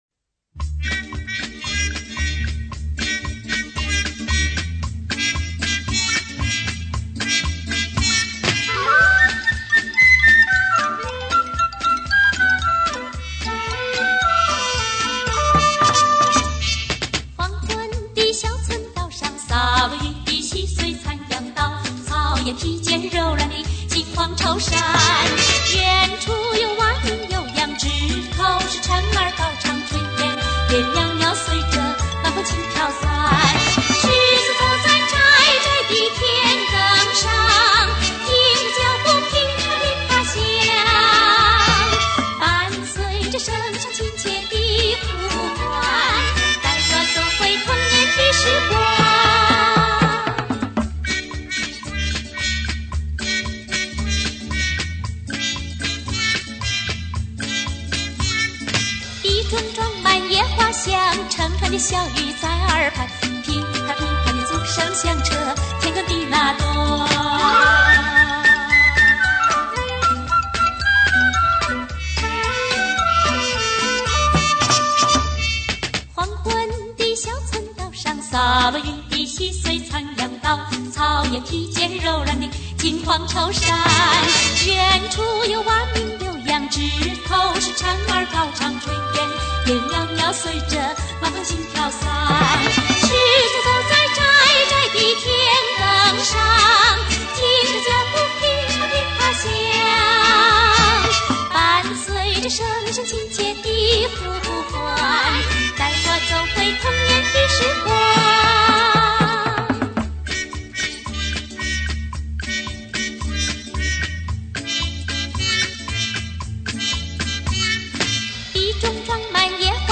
台湾校园歌曲
内蒙鄂尔多斯民歌
印尼民歌